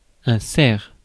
Ääntäminen
Ääntäminen France (Paris): IPA: [ɛ̃ sɛʁ] Tuntematon aksentti: IPA: [sɛʁ] Haettu sana löytyi näillä lähdekielillä: ranska Käännös Substantiivit 1. елен {m} (elén) Suku: m .